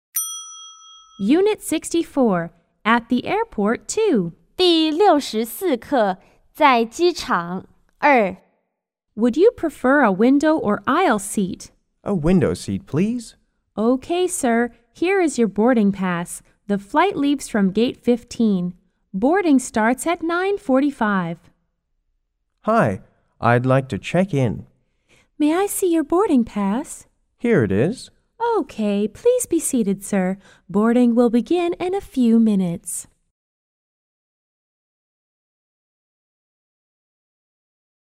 R= Receptionist T= Traveler